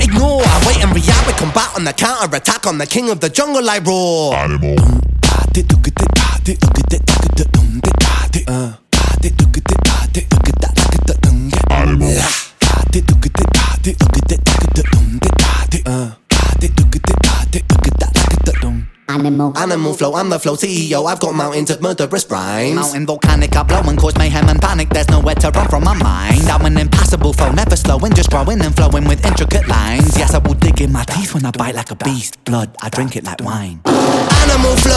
Жанр: Хип-Хоп / Рэп / Альтернатива
Alternative Rap, Hip-Hop, Rap